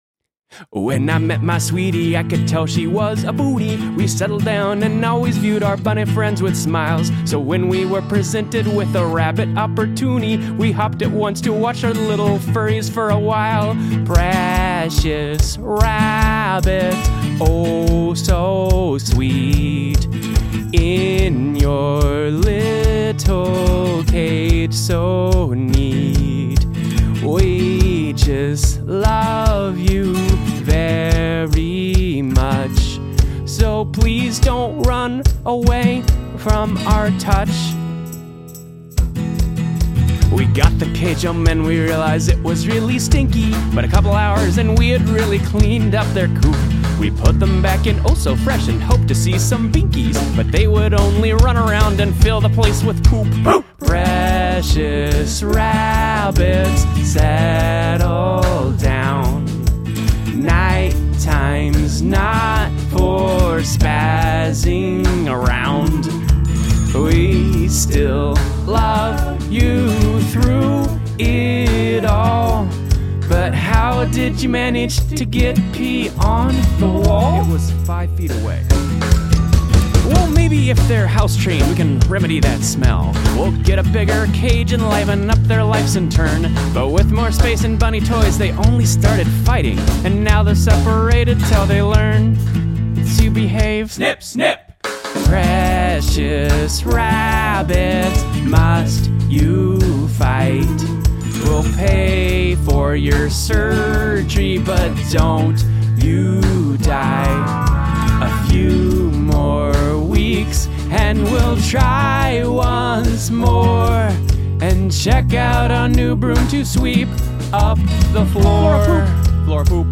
Gradual emphasis of repetitions